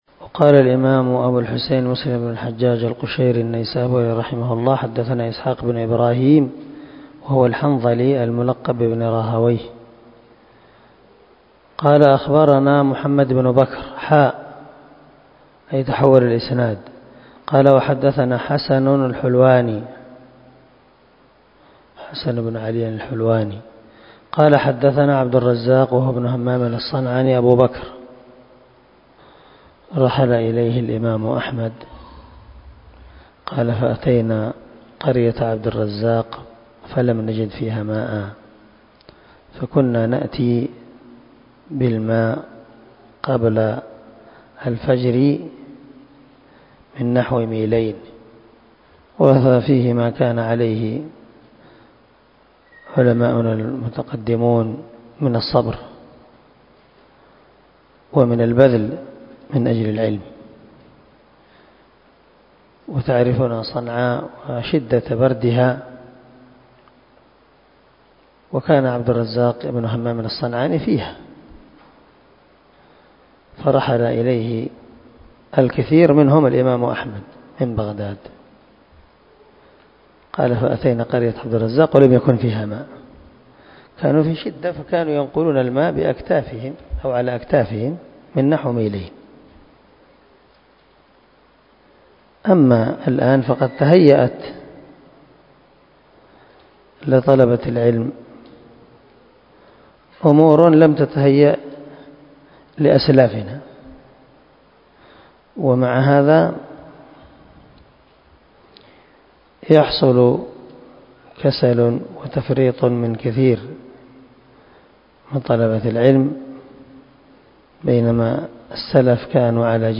نصيحة قيمة في الصبر والتحمل في سبيل العلم من درس مسلم